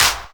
Claps
XF_clapC12.wav